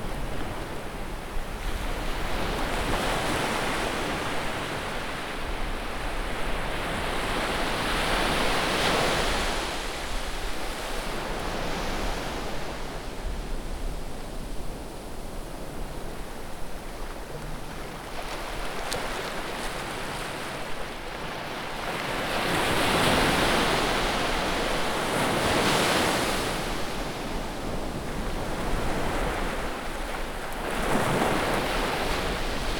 sounds of the ocean